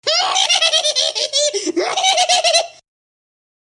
Sound Effects
Creepy Weird Laugh